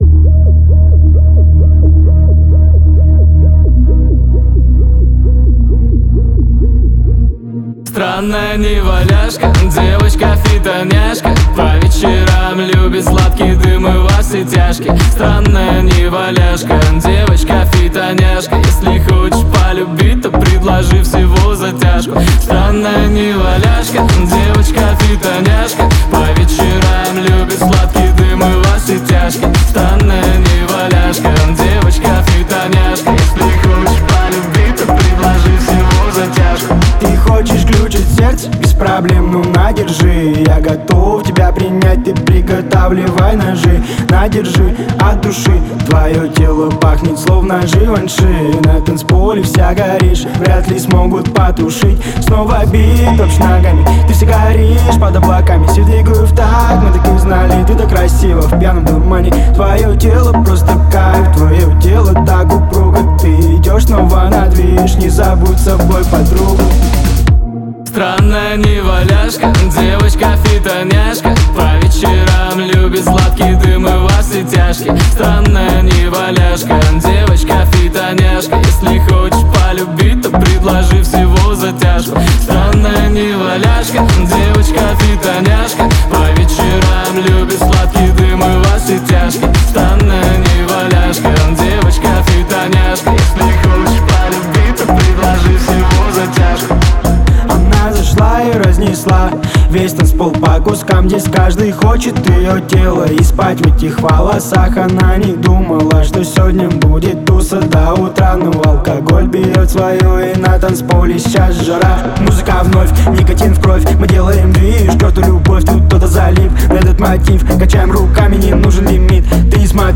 • Категория: Русские песни